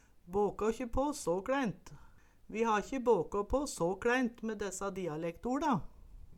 båkå ikkje på så kLæint - Numedalsmål (en-US)